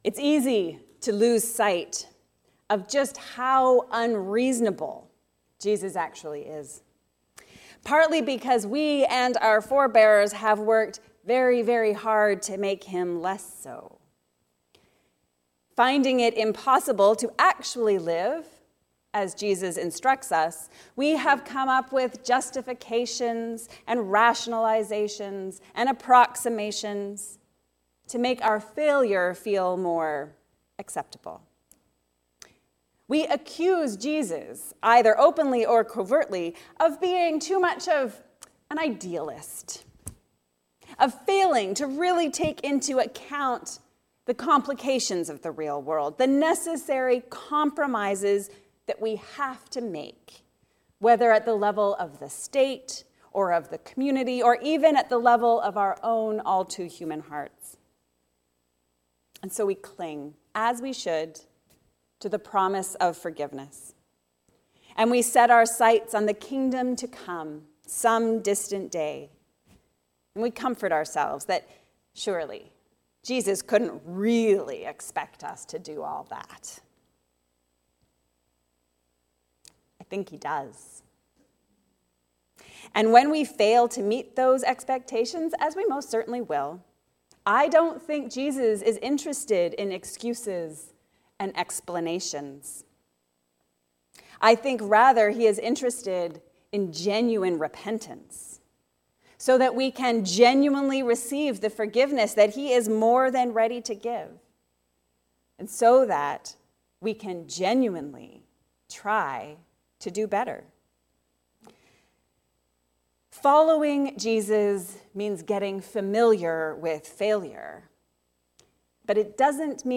Embracing the unreasonable expectations of Jesus. A sermon on Matthew 18:21-35.